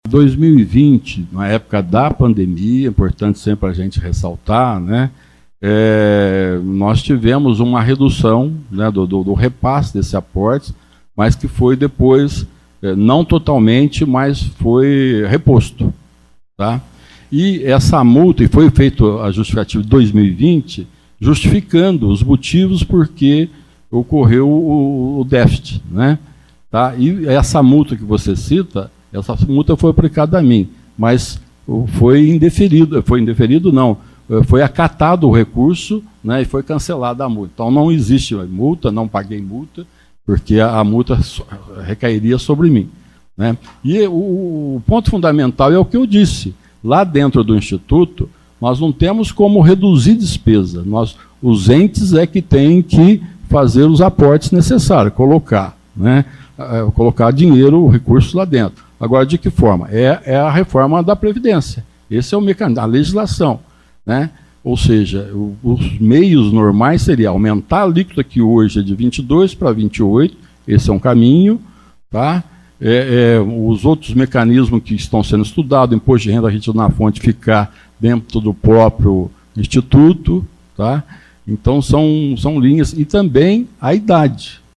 Questionado pelos vereadores, Sérgio atribuiu os déficits às oscilações do mercado financeiro durante a pandemia da COVID-19 e afirmou que a multa aplicada por suposta má gestão foi anulada.